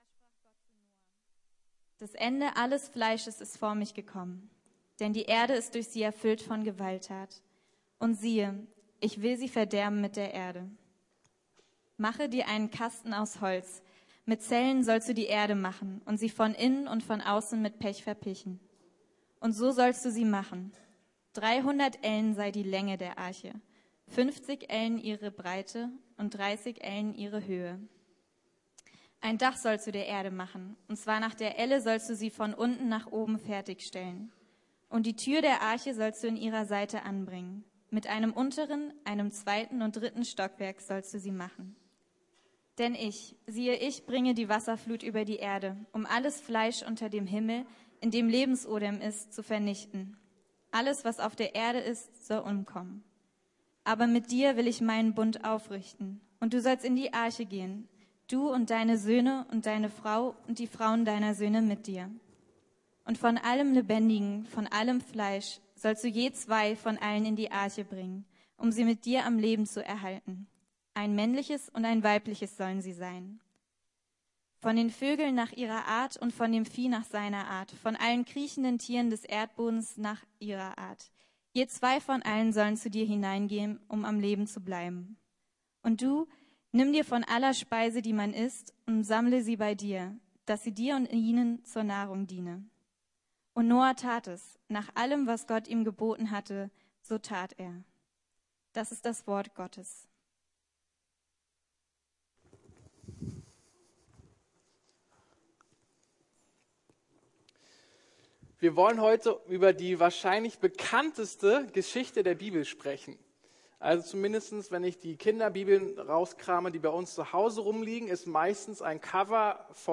Aus Glauben leben. Gott erleben. ~ Predigten der LUKAS GEMEINDE Podcast